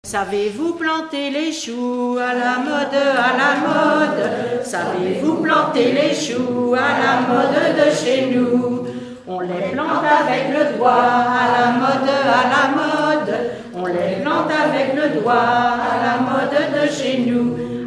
Mémoires et Patrimoines vivants - RaddO est une base de données d'archives iconographiques et sonores.
L'enfance - Enfantines - rondes et jeux
Pièce musicale inédite